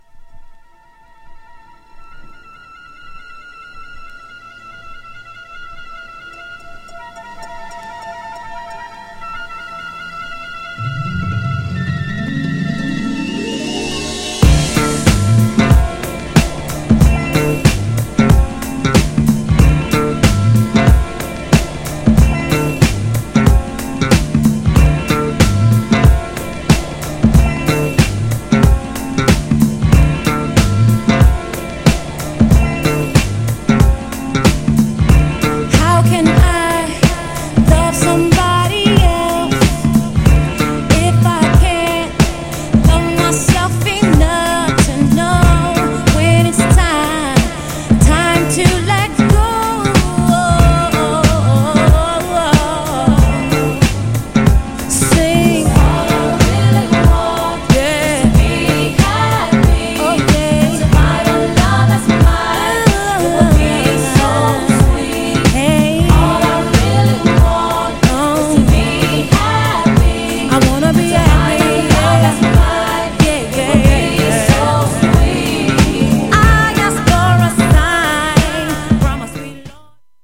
GENRE R&B
BPM 96〜100BPM